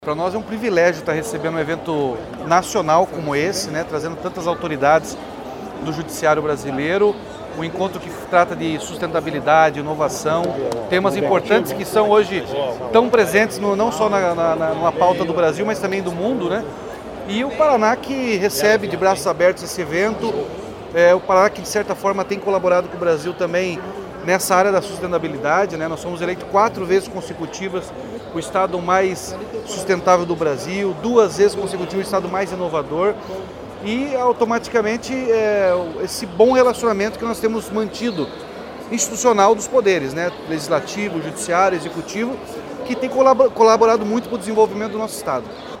Sonora do governador Ratinho Junior sobre o Congresso Brasileiro da Magistratura